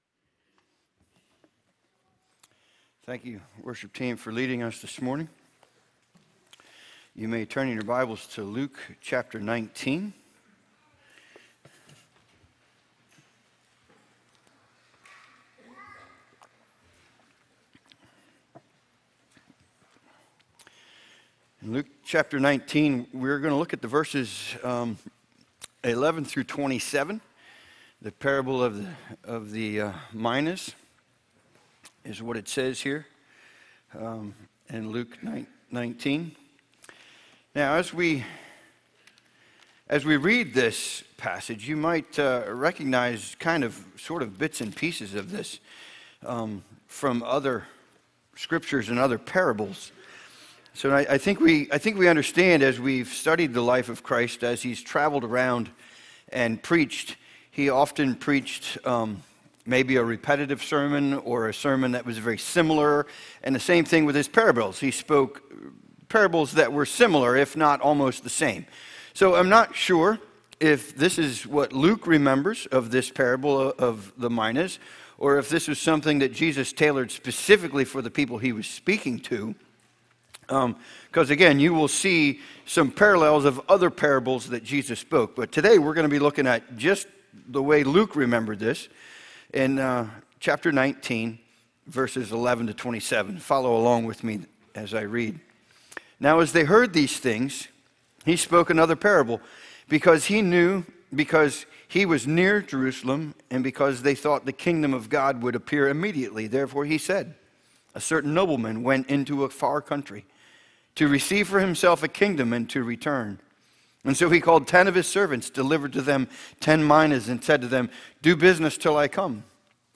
Sermon Archive | - New Covenant Mennonite Fellowship
From Series: "Sunday Morning - 10:30"